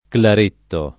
claretto [ klar % tto ]